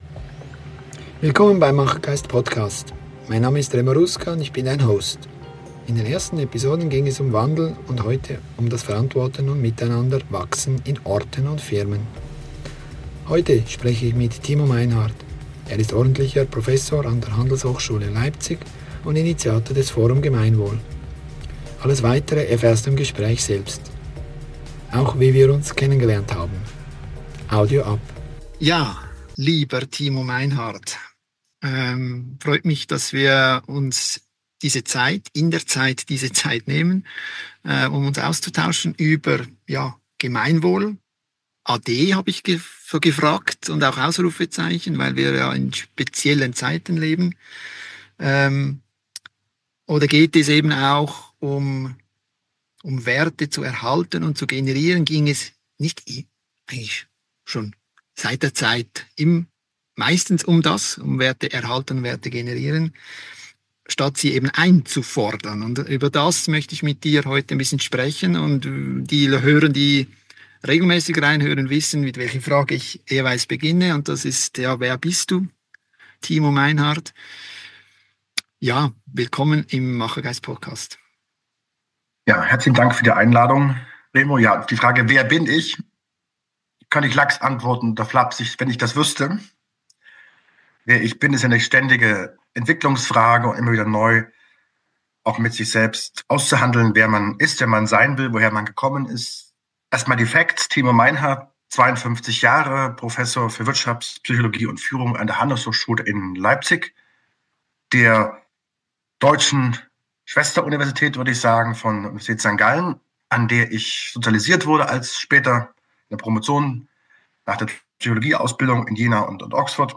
ein Gespräch über Gemeinwohl sowie das Entfalten und Entwickeln ~ Machergeist Podcast